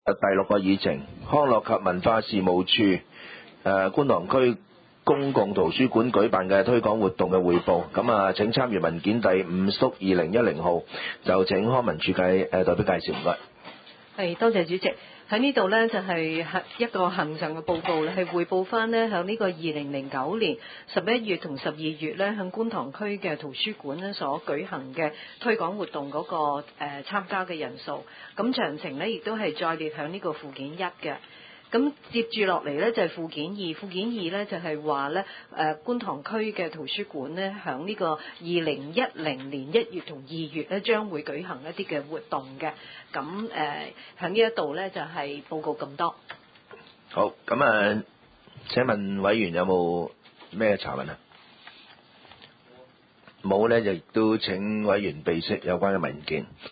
第十五次會議記錄
九龍觀塘同仁街6號觀塘政府合署3樓觀塘民政事務處會議室